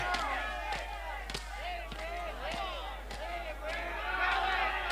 -This episode is another one which has the sampled crowd chant, only it's heard
much clearer in this episode than any previous one.
chant yu yu hakusho.mp3